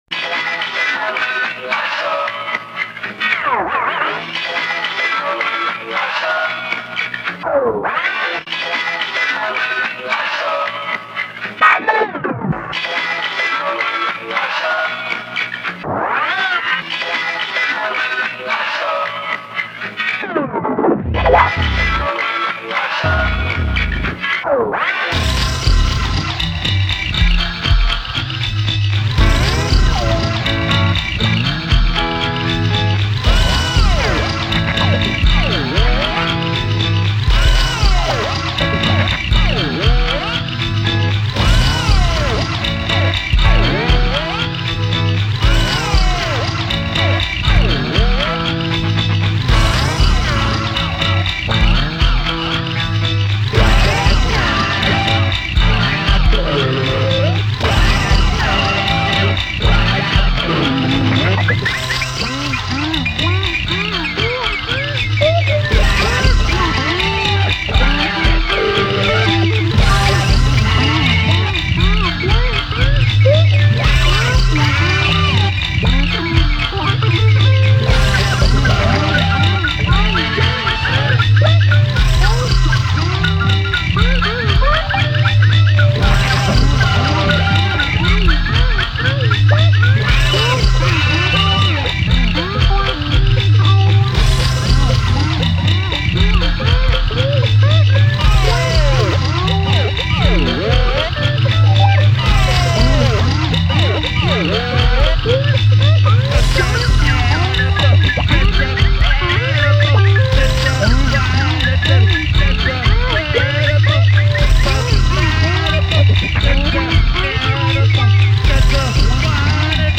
-       Recorded at Brooklyn’s Rare Book Room